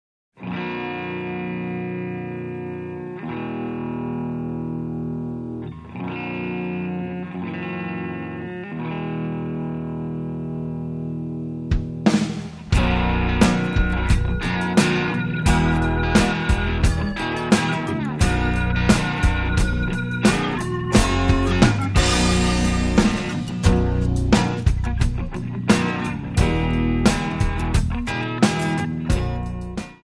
Backing track Karaoke
Country, 2000s